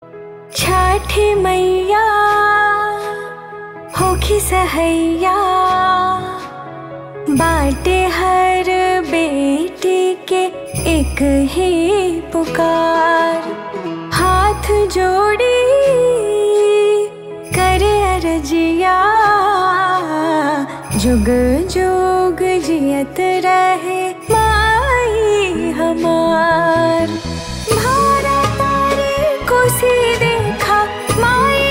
Bhojpuri Songs
• Simple and Lofi sound
• High-quality audio
• Crisp and clear sound